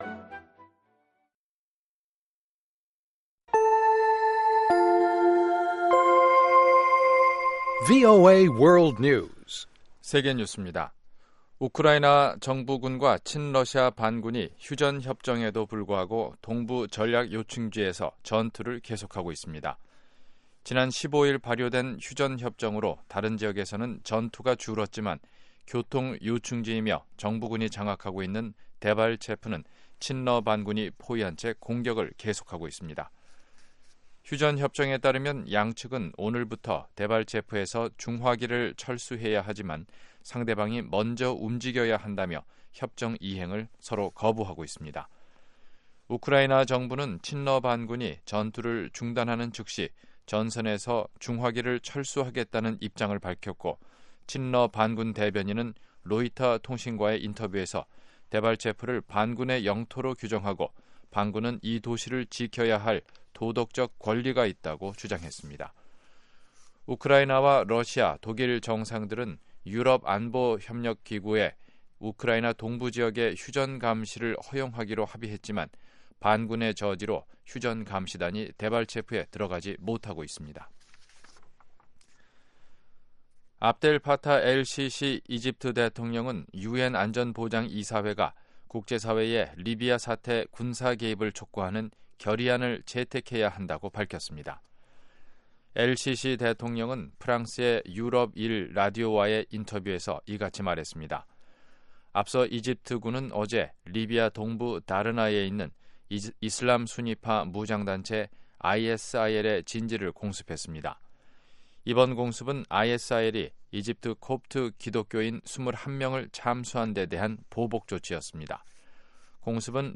VOA 한국어 방송의 간판 뉴스 프로그램 '뉴스 투데이' 3부입니다.